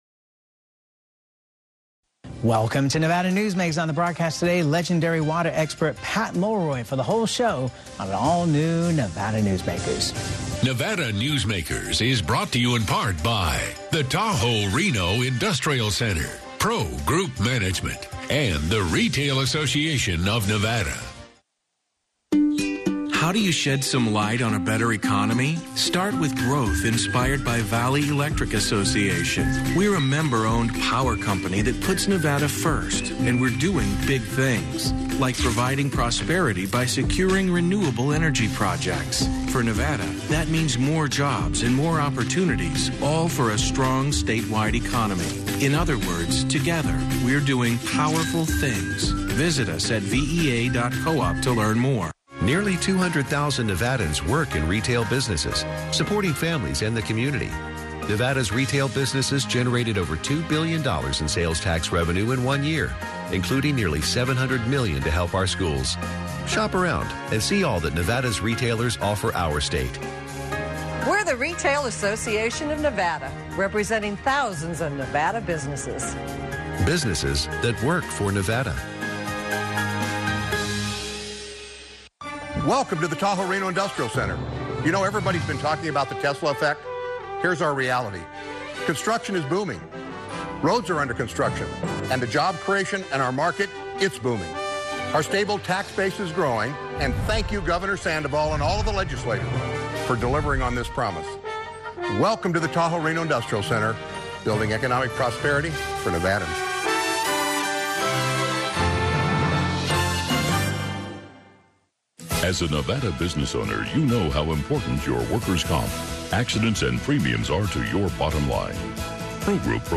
Interview with Pat Mulroy (Nevada Newsmakers)